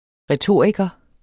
retoriker substantiv, fælleskøn Bøjning -en, -e, -ne Udtale [ ʁεˈtoˀɐ̯igʌ ] Betydninger 1. veltalende person der mestrer retorikkens grundprincipper Hun er en dygtig retoriker, som leverer ved debatbordet Inf2012 Information (avis), 2012.